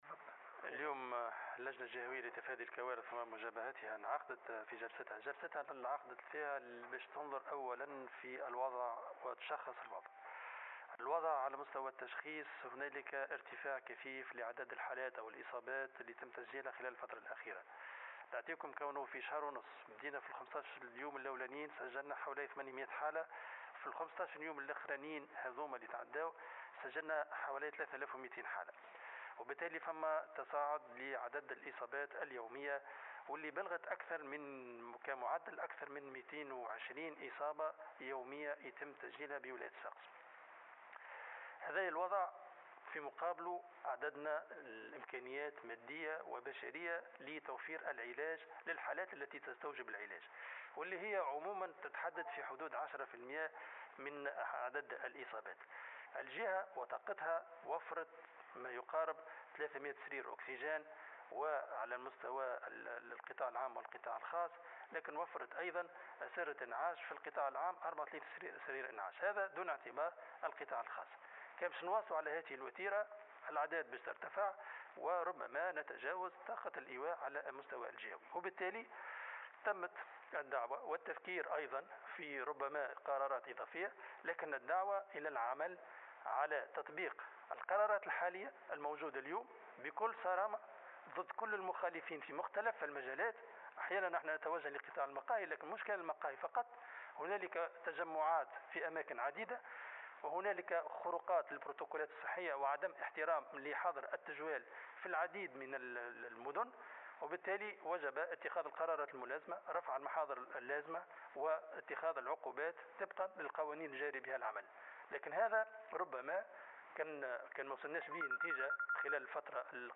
وأضاف في تصريح اليوم لمراسلة "الجوهرة أف أم" على اثر اجتماع اللجنة الجهوية لتفادي الكوارث و مجابهتها بولاية صفاقس، أنه تم النظر في عدد من المقترحات للحد من انتشار العدوى بالفيروس على غرار تقديم موعد حظر التجوال و ايقاف الدروس، التي سيتم النظر فيها بالتنسيق مع اللجنة الوطنية .